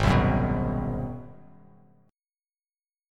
Listen to Ab7#9 strummed